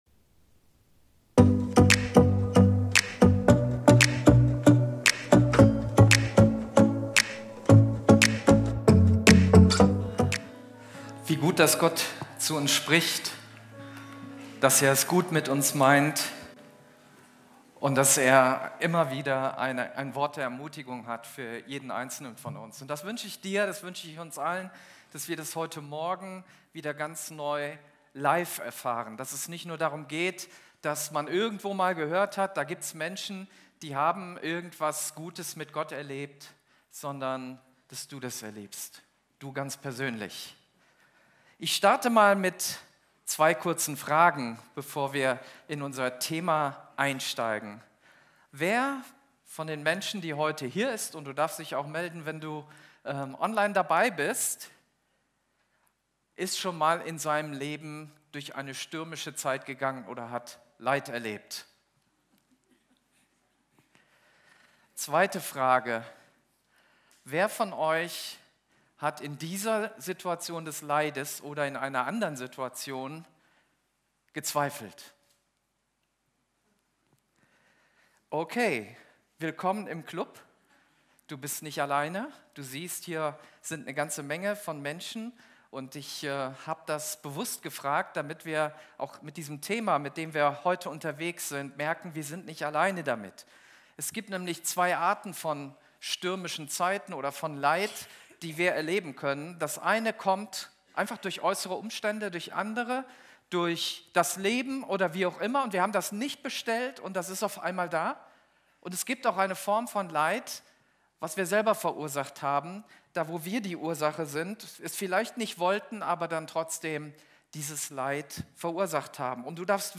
Live-Gottesdienst aus der Life Kirche Langenfeld.
Sonntaggottesdienst